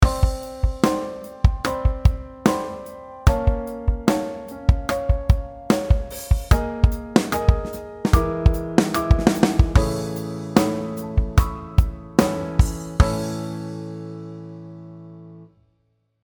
Here we are using a 5:4 polyrhythm to give the impression of speeding up the tempo – kick and snare in quintuplet subdivision and hi-hats on a four note per beat subdivision
metric mod - polyrhythm.mp3